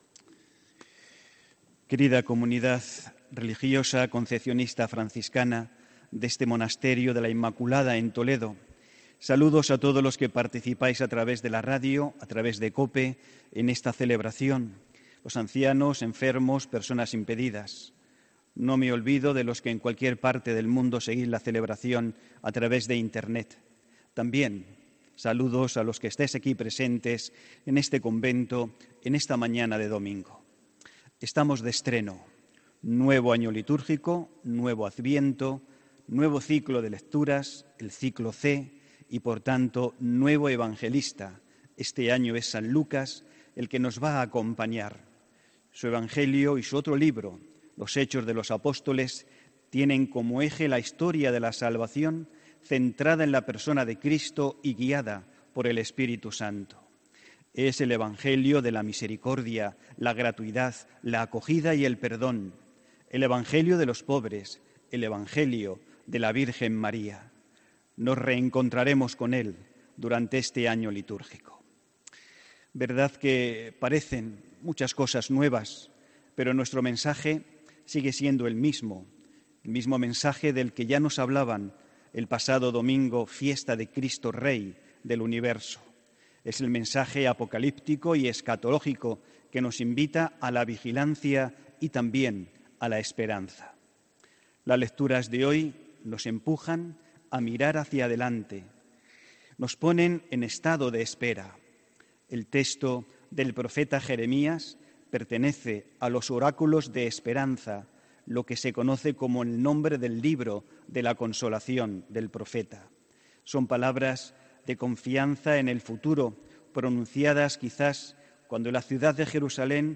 HOMILÍA 2 DE DICIEMBRE DE 2018